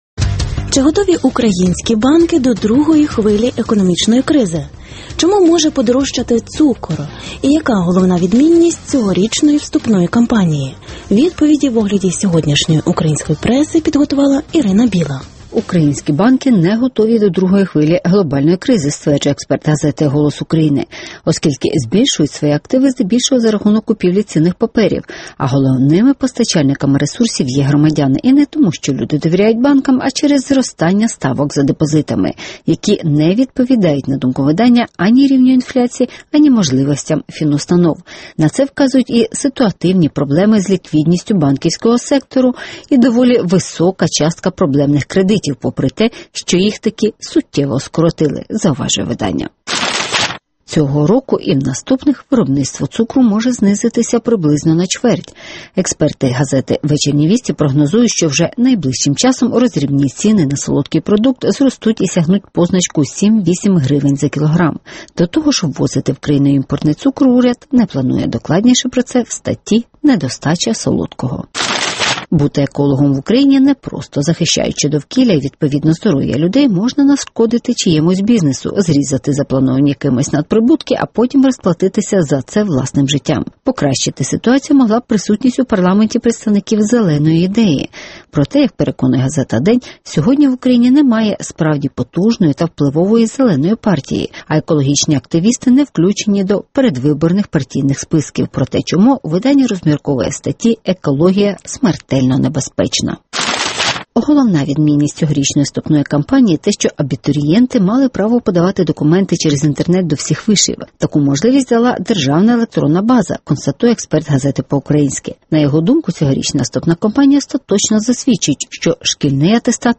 Екологія – смертельно небезпечна (огляд преси)